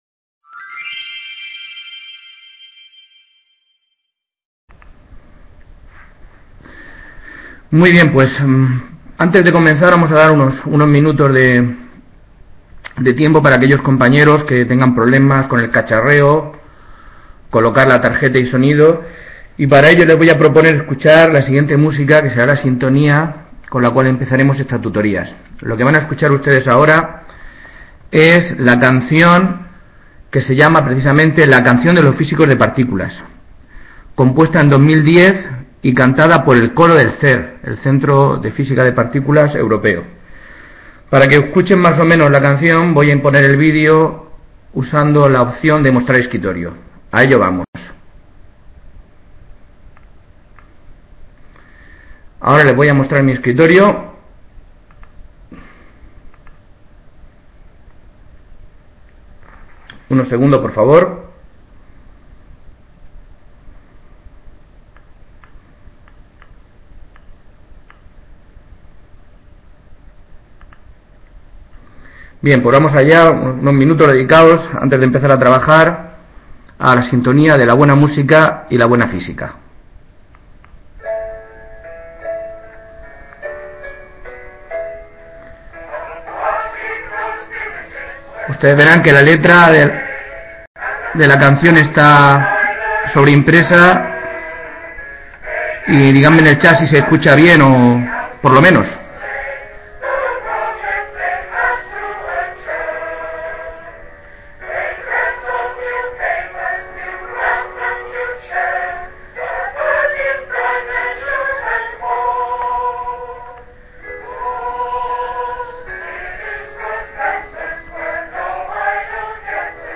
Parte I Description Primera parte de la primera tutoría virtual de la asignatura Vibraciones y Onda. 2º Curso.